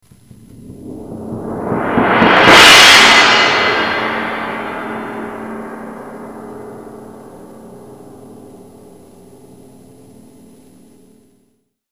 Thundersheet.mp3